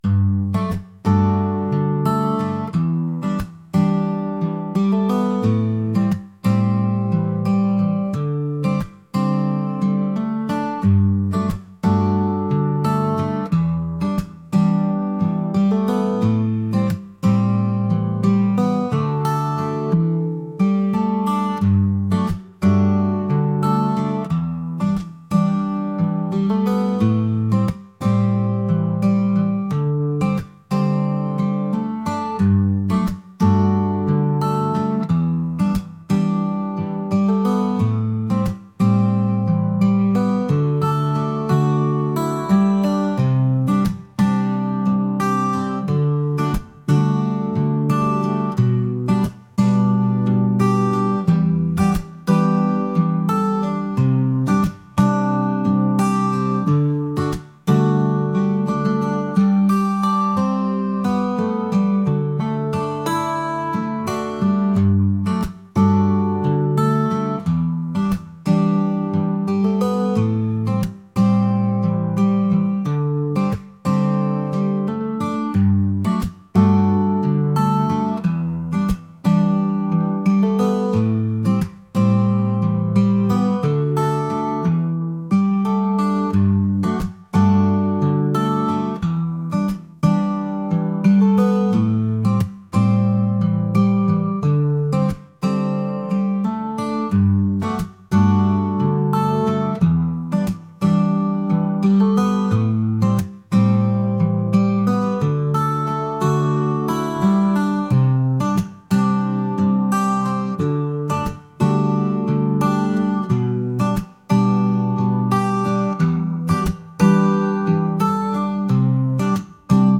acoustic | pop | lofi & chill beats